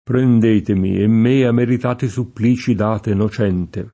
supplizio [Suppl&ZZLo] s. m.; pl. -zi — antiq. supplicio [Supplo]; pl. -ci; es.: prendetemi e me a’ meritati supplici date nocente [